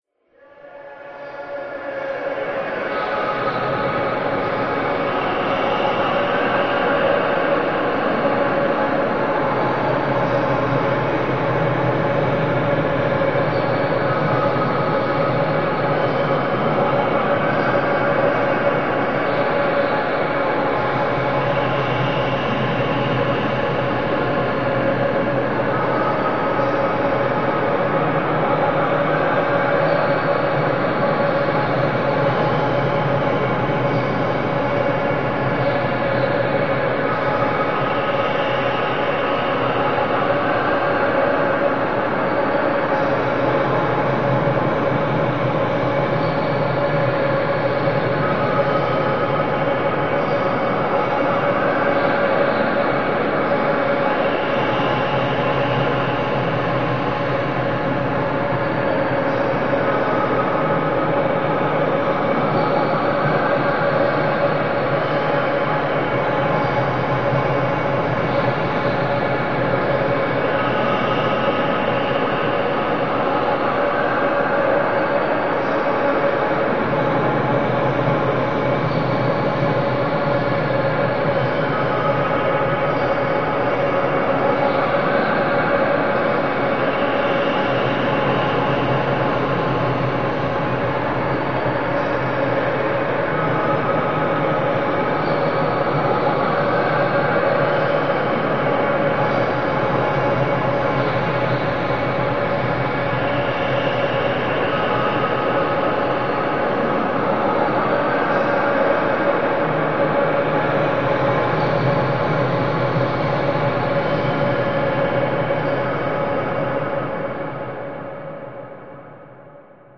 Distant Choral Tribal Textures In Large Open Space Choral, Tribal, Large